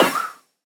Minecraft Version Minecraft Version snapshot Latest Release | Latest Snapshot snapshot / assets / minecraft / sounds / mob / breeze / deflect2.ogg Compare With Compare With Latest Release | Latest Snapshot
deflect2.ogg